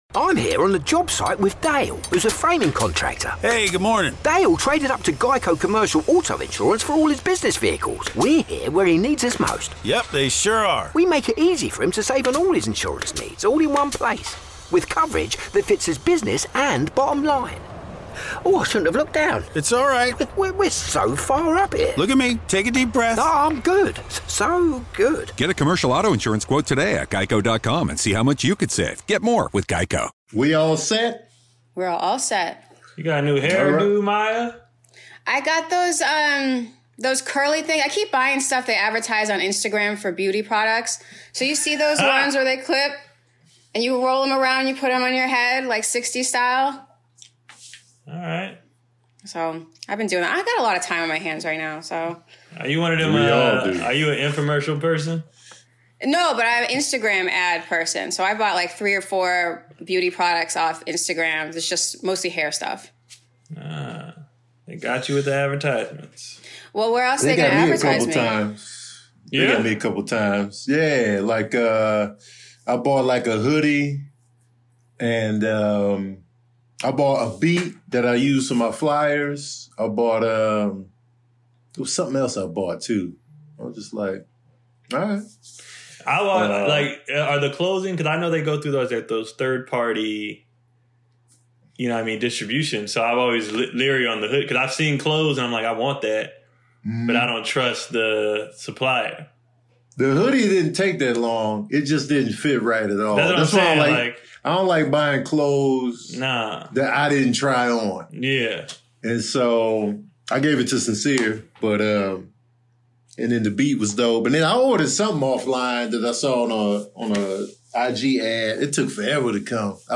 And unfortunately we're still in lockdown, so once again we're stuck with sub par audio quality, so we apologize for that. BUT this episode we're getting real personal, talking about a lot of things they don't talk about when you're raising boys in particular.